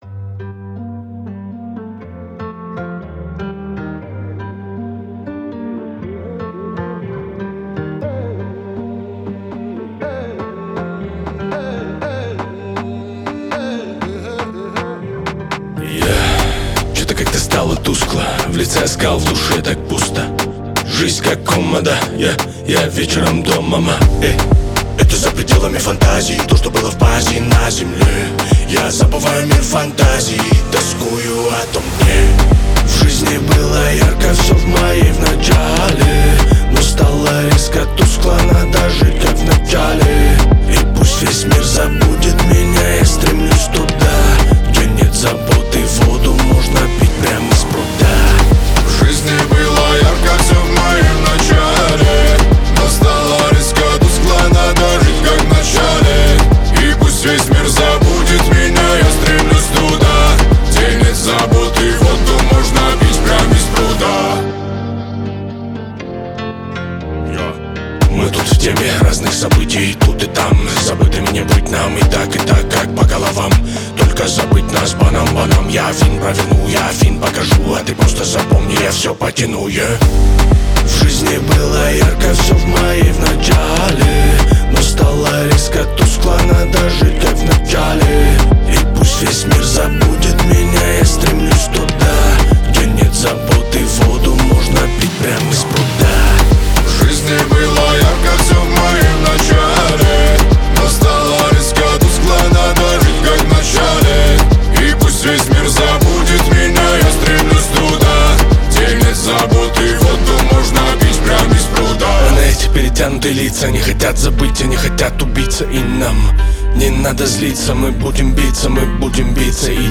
Качество: 320 kbps, stereo
Поп музыка, Рэп